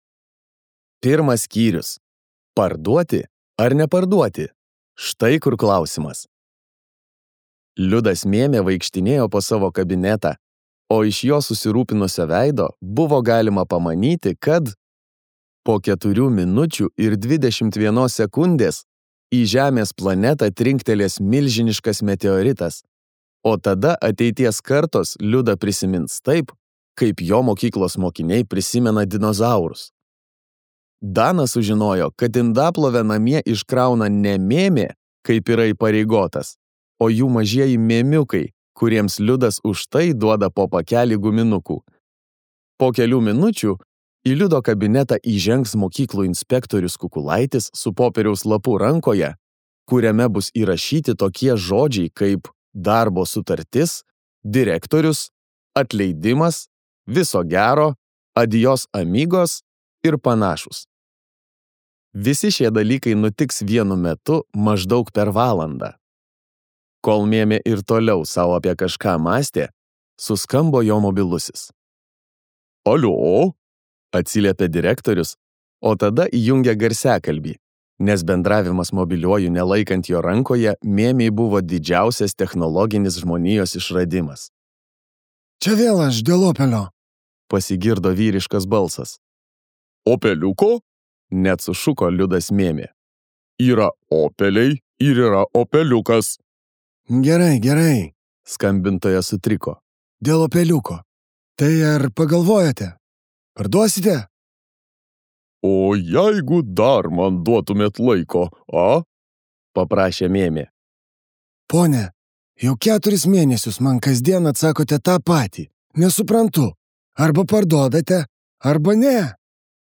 Skaityti ištrauką play 00:00 Share on Facebook Share on Twitter Share on Pinterest Audio Domas ir Tomas.